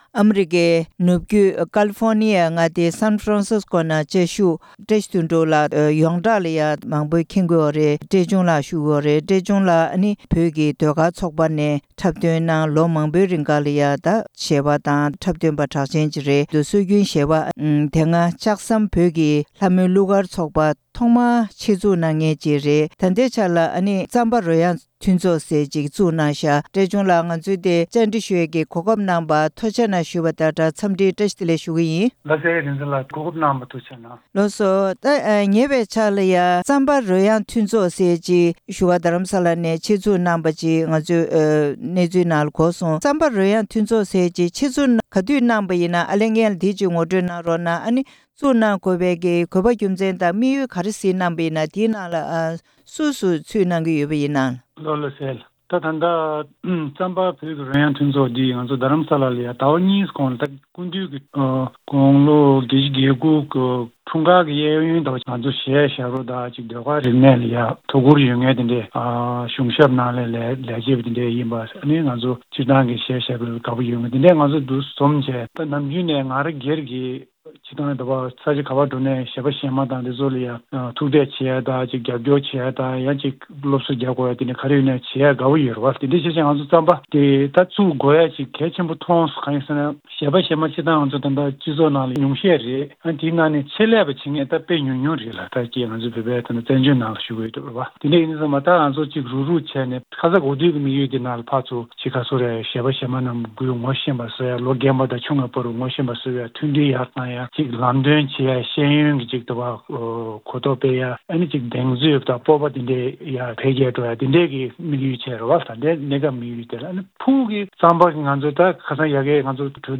གནས་འདྲི་ཞུས་པ་ཞིག་གསན་གནང་གི་རེད་།